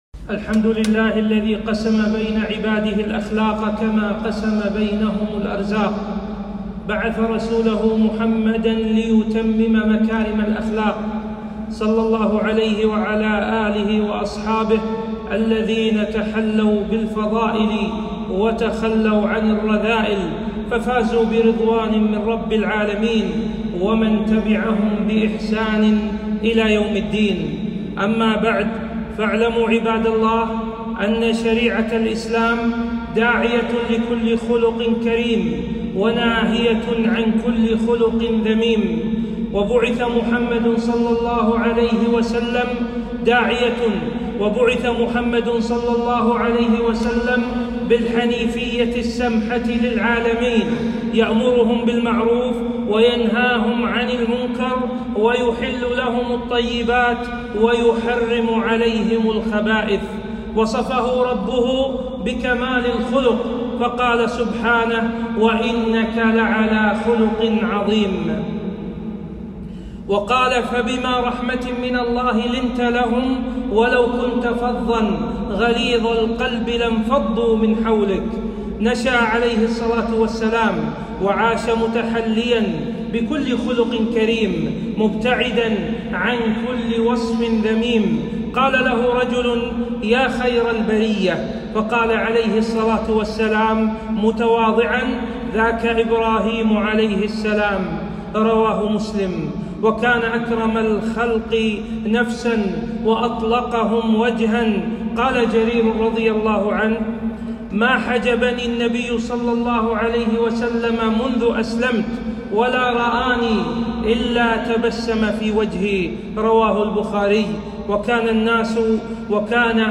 خطبة - مكارم الأخلاق